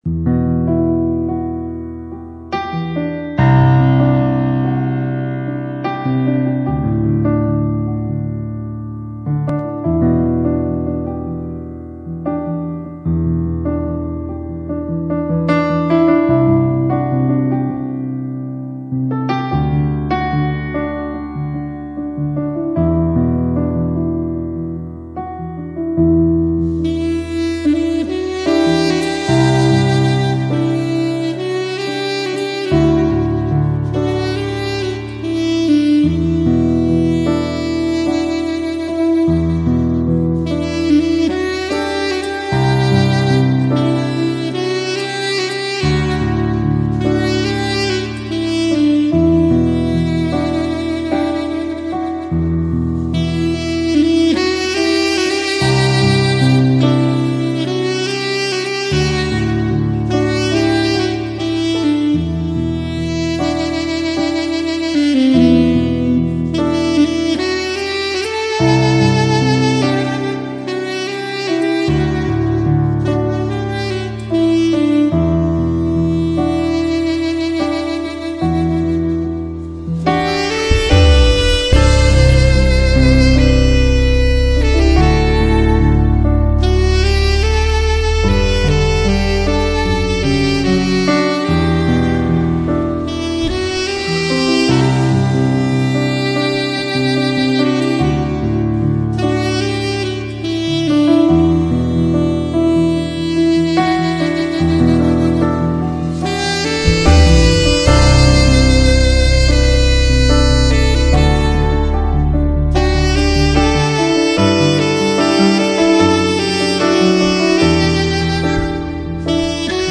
Instr